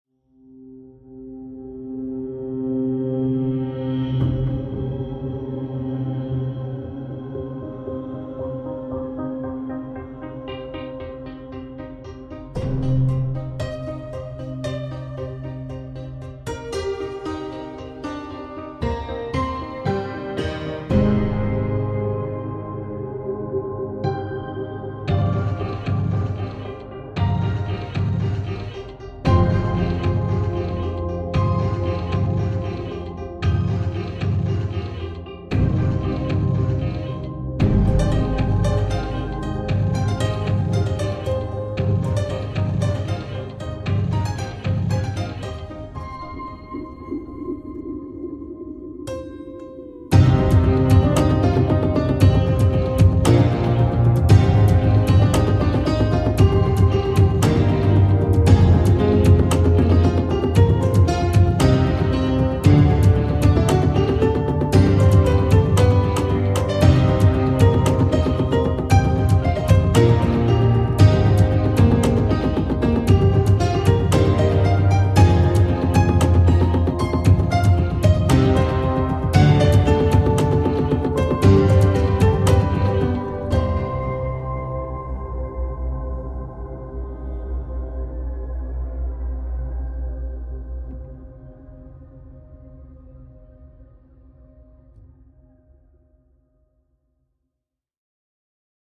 Soundiron Delphi Piano 02 Knightsen Boxgrand 是一款基于 Kontakt 的虚拟钢琴音源，它采样了一架罕见的老式立式钢琴，拥有独特的声音和氛围。这款音源不仅提供了原始的钢琴音色，还包含了由钢琴声音加工而成的多种环境垫音、无人机、冲击音效等，适合用于创造性的音乐制作和声音设计。
- 两个麦克风位置的钢琴音符、释放音、簇音、滑音、无音键和踏板噪音
- 11个多采样的环境垫音、演变无人机、冲击音效，由原始声音制作而成